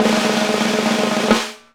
WIRBEL     1.wav